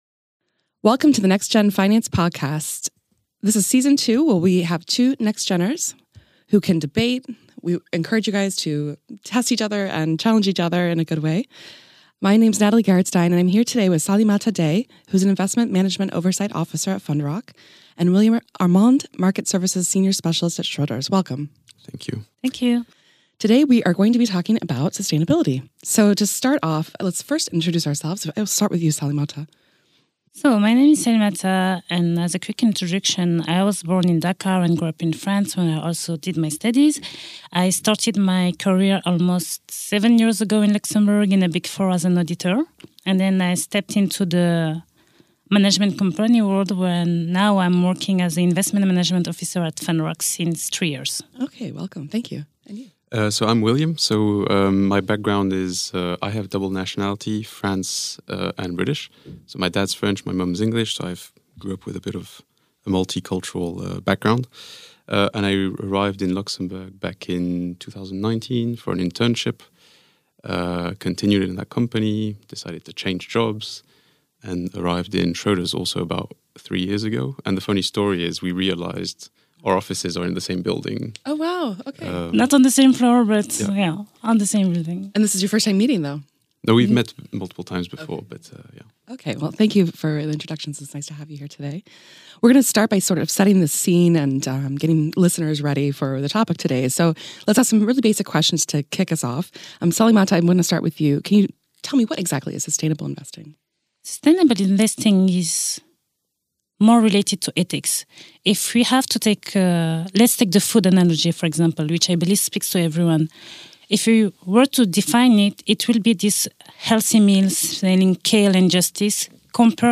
The Finance NextGen podcast series consists of dialogues between an experienced financial services professional and a “nextgener”, namely, a young professional the early stages of their career. They touch upon current issues impacting the fund industry from their very own perspective.